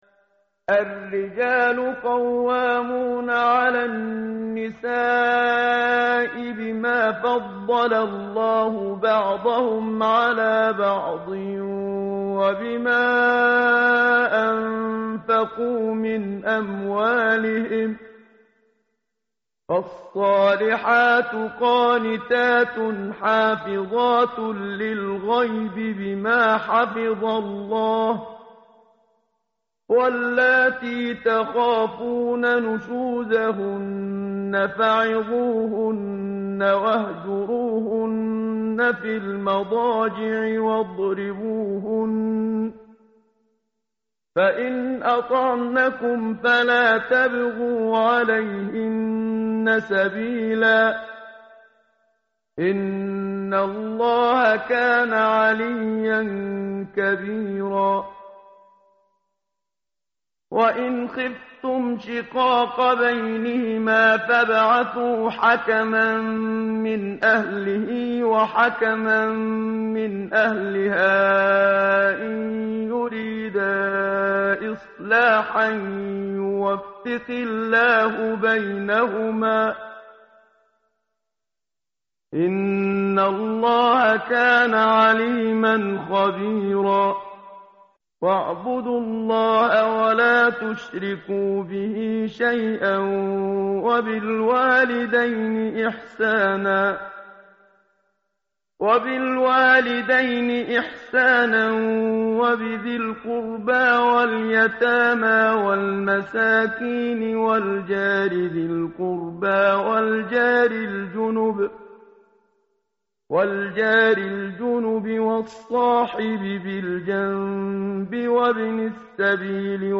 tartil_menshavi_page_084.mp3